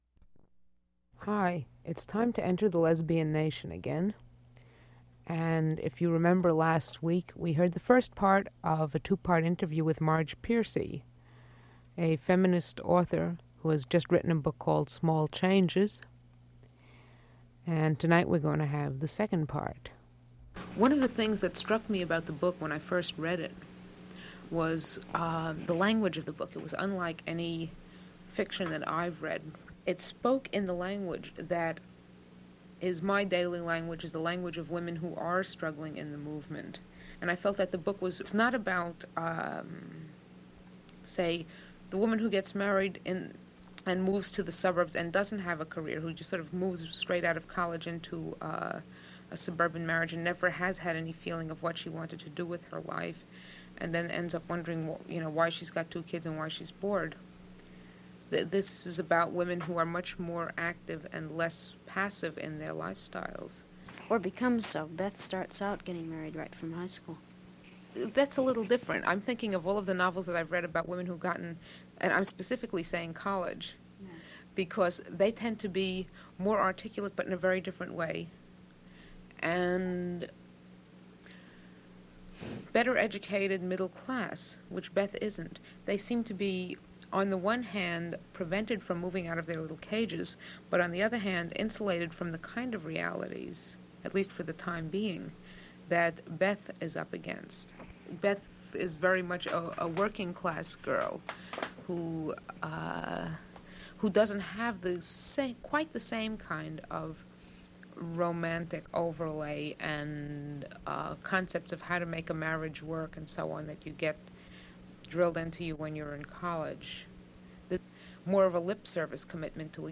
Download File Title Lesbian Nation, September 10, 1973 Subject Lesbian Community, Author, Writing Description Part two of an interview about Marge Piercy's newest book "Small Changes." Discussion included topics like the value of writing about working class characters and language in writing and speech.
1/4 inch audio tape